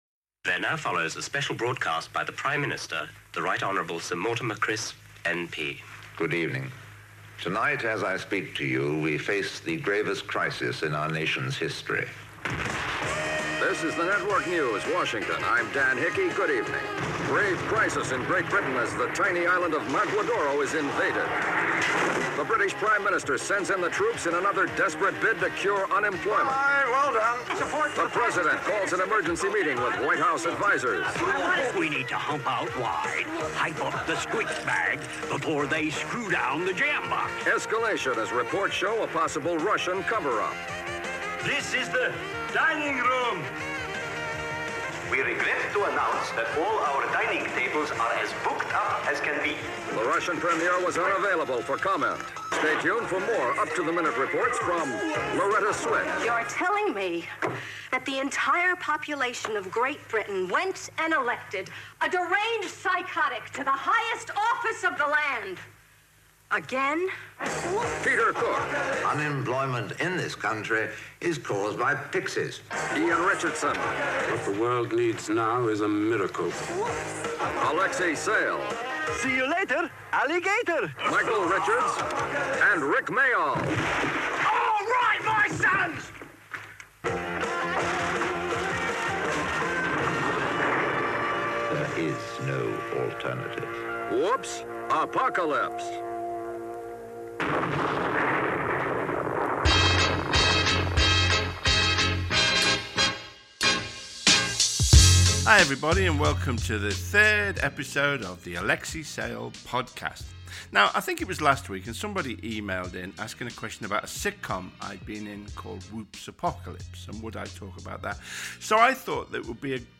Alexei talks to comedy writers Andrew Marshall and David Renwick about their careers including the sitcom ''Whoops Apocalypse' and 'Alexei Sayle's Stuff'.Stick around until the end of the show to hear some more listeners' emails.